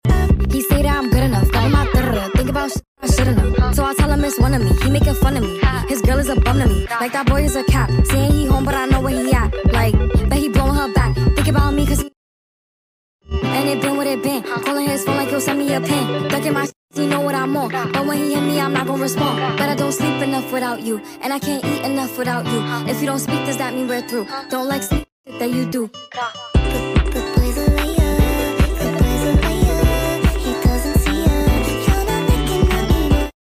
sped up .